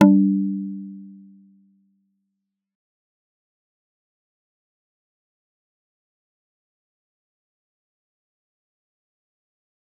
G_Kalimba-G3-f.wav